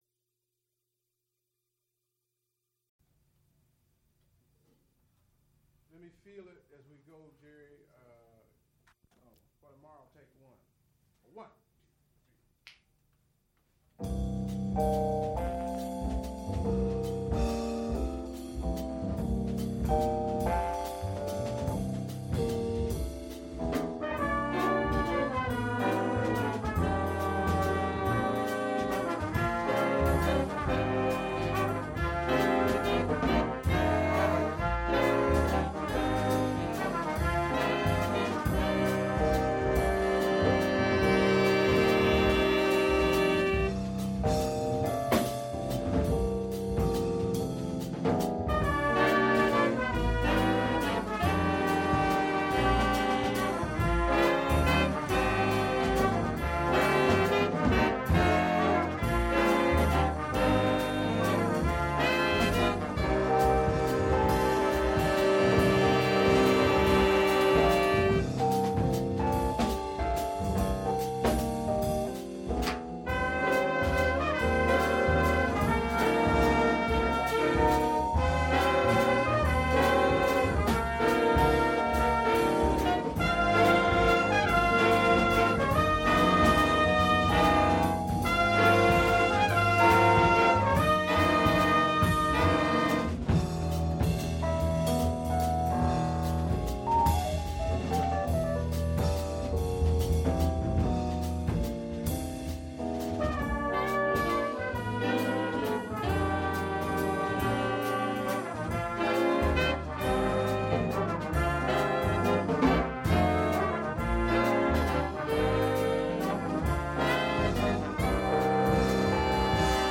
vocalist
piano
Recorded live December 11, 1979, University of Pittsburgh.
Extent 3 audiotape reels : analog, half track, 15 ips ; 12 in.
Jazz--1971-1980